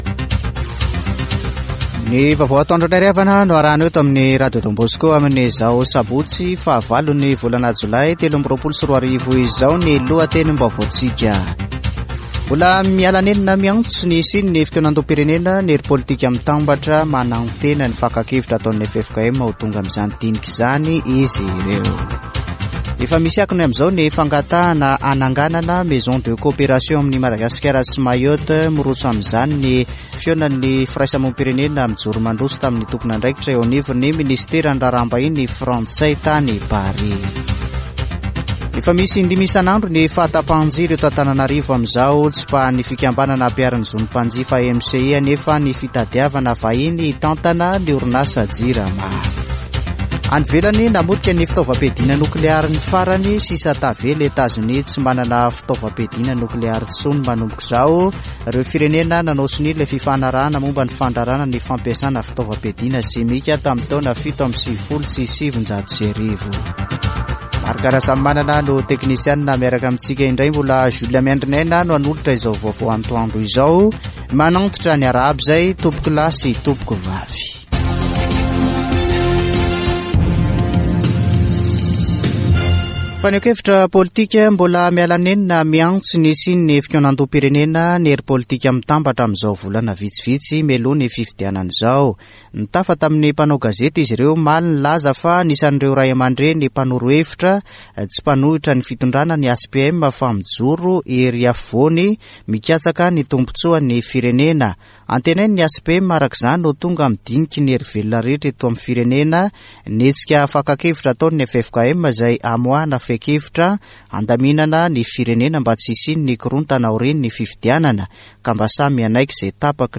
[Vaovao antoandro] Sabotsy 8 jolay 2023